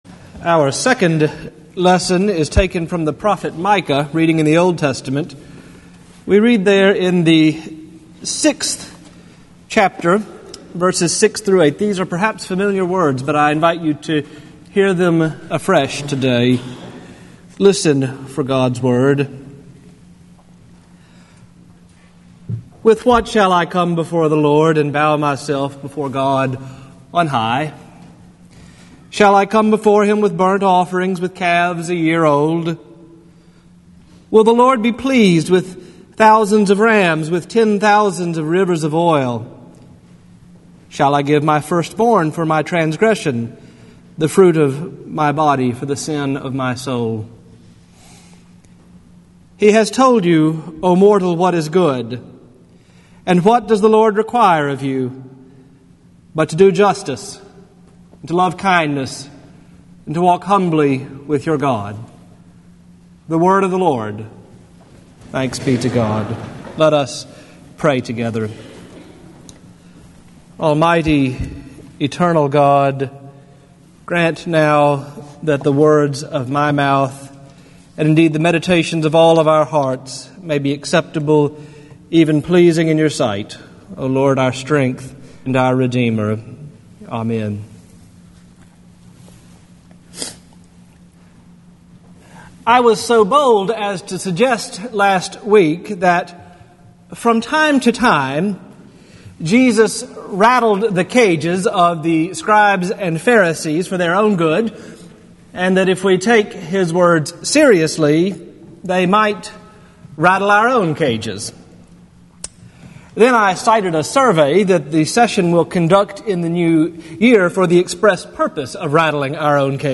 Sermon Archive 2011 | Morningside Presbyterian Church